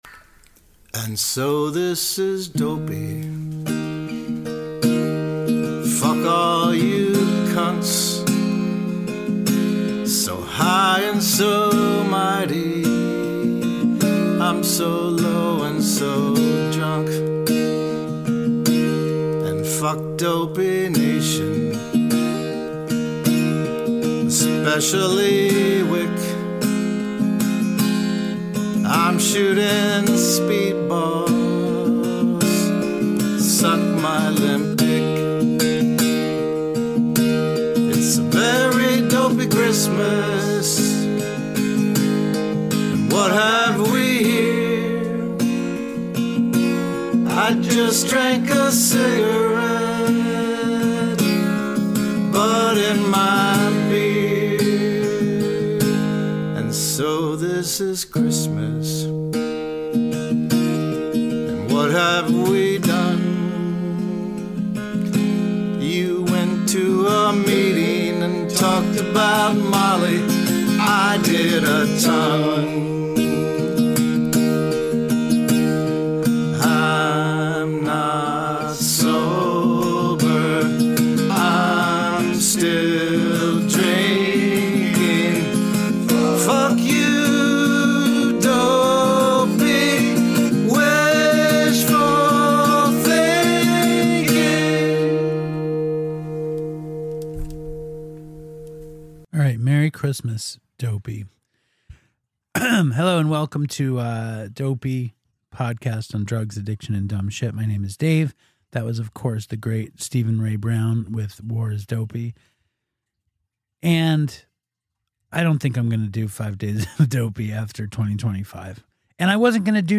We start with me alone reading spotify comments and considering not doing 5 shows a week!